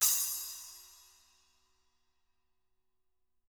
Index of /90_sSampleCDs/ILIO - Double Platinum Drums 1/CD4/Partition H/SPLASH CYMSD